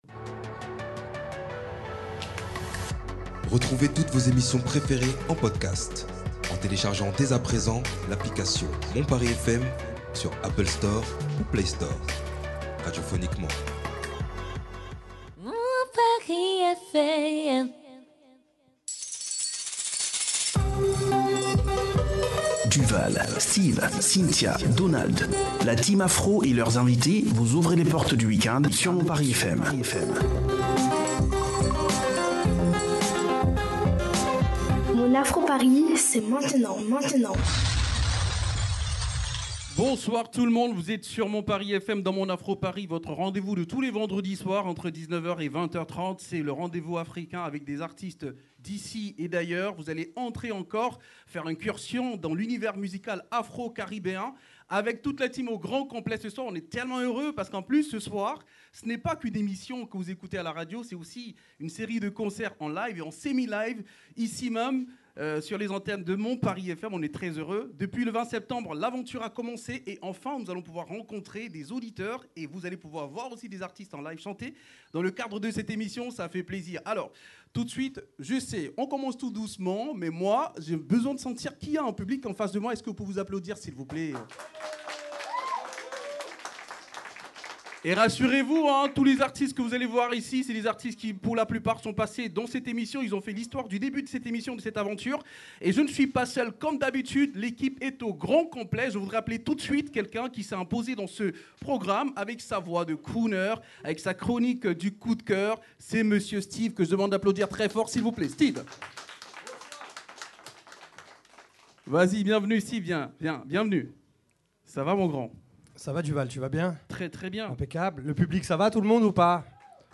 Le Live de la rentrée avec l'équipe de Mon Afro Paris devant le public du centre Eugène Oudiné, c'est maintenant !